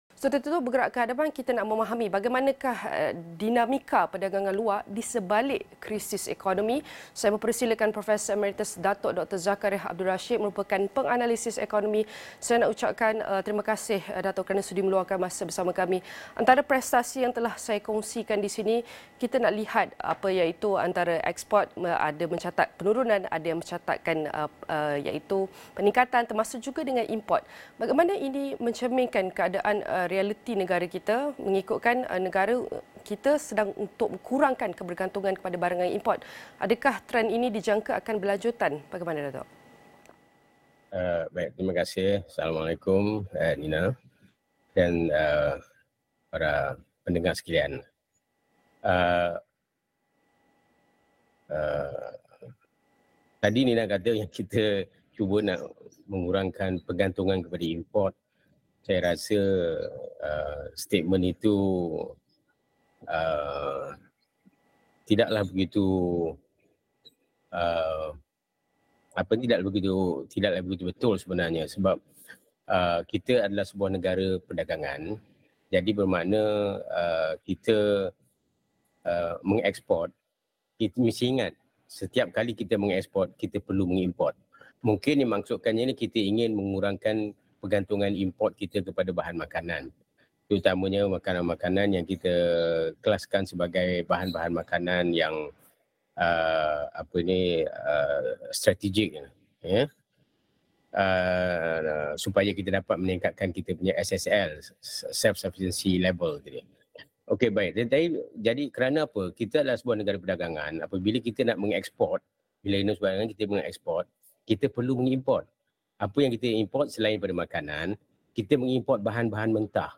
Prestasi eksport Malaysia bagi November 2023 susut sebanyak 5.9 peratus kepada RM7.6 bilion berbanding tempoh sama pada 2022. Kupasan bersama penganalisis ekonomi